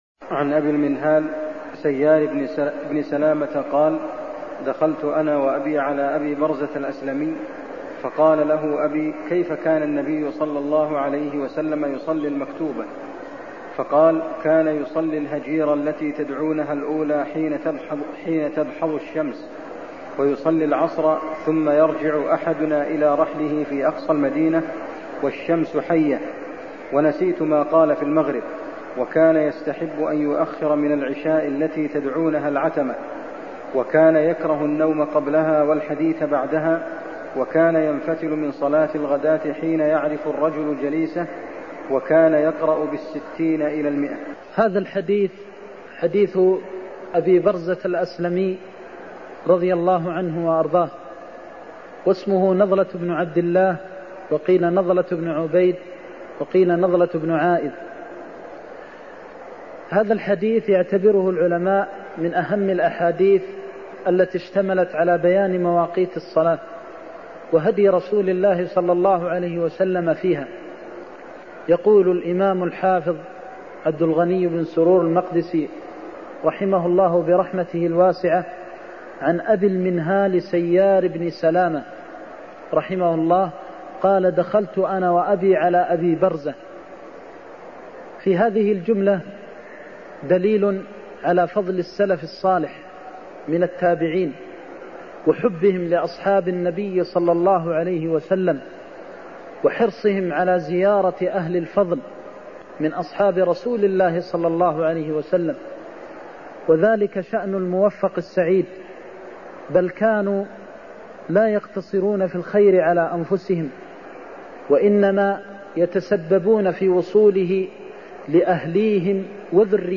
المكان: المسجد النبوي الشيخ: فضيلة الشيخ د. محمد بن محمد المختار فضيلة الشيخ د. محمد بن محمد المختار كيف كان النبي يصلي المكتوبة (47)